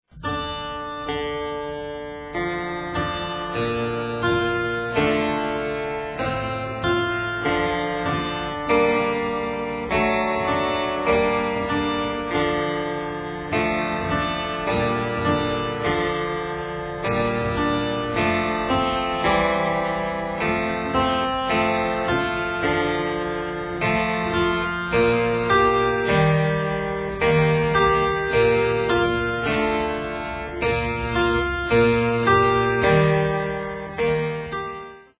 Early stereo master tapes, recorded at 30 ips